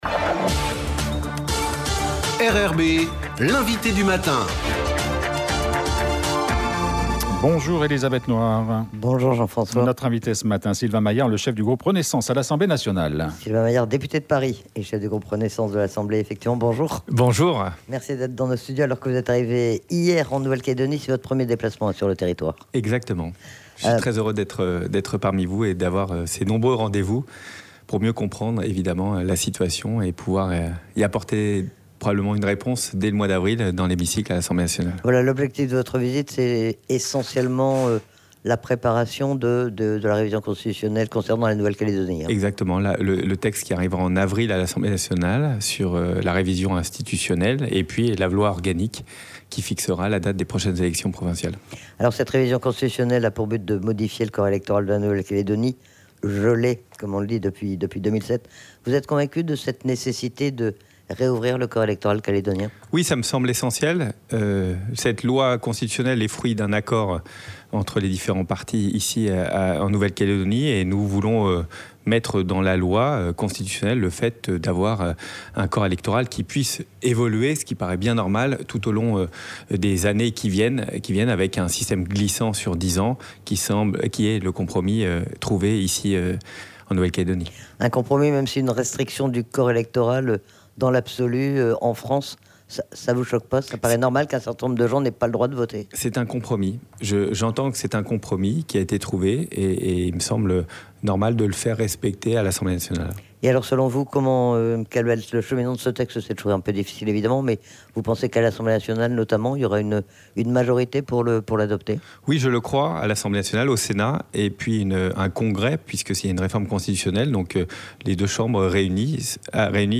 L'INVITE DU MATIN : SYLVAIN MAILLARD
Sylvain Maillard est aussi interrogé sur le remaniement ministériel intervenu tôt ce matin.